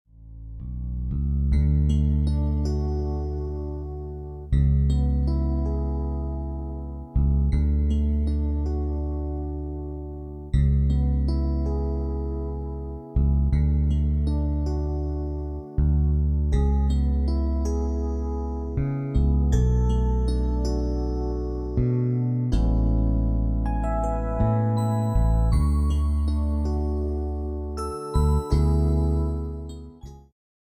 充滿動感和時代感
有伴奏音樂版本
伴奏音樂